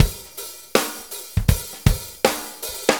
Shuffle Loop 23-06.wav